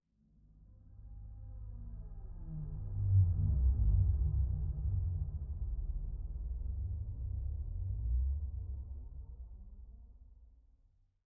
Minecraft Version Minecraft Version latest Latest Release | Latest Snapshot latest / assets / minecraft / sounds / ambient / underwater / additions / dark3.ogg Compare With Compare With Latest Release | Latest Snapshot